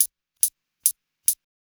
Hi Hat 05.wav